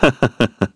Shakmeh-Vox_Human_Happy1_kr.wav